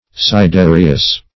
Sidereous \Si*de"re*ous\, a.
sidereous.mp3